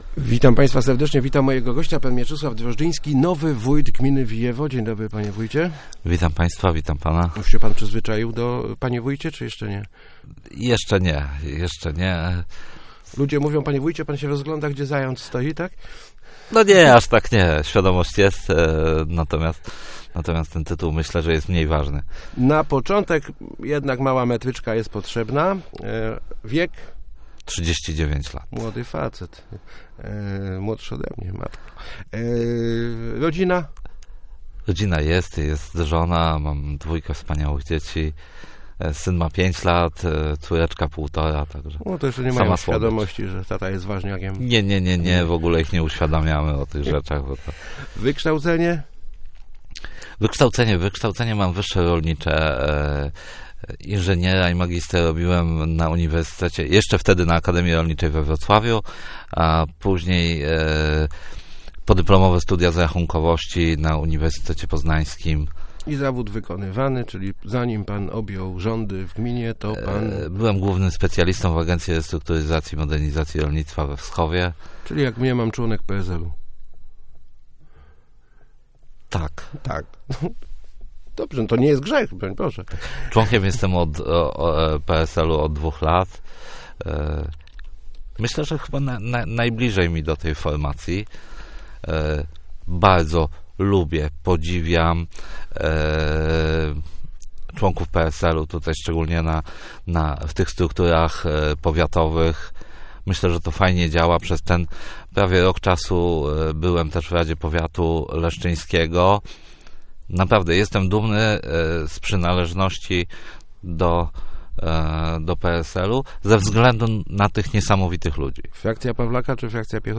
Nie chodzi nam o robienie rewolucji, tylko o przyspieszenie ewolucji gminy – mówi� w Rozmowach Elki Mieczys�aw Dro�d�y�ski, nowy wójt gminy Wijewo.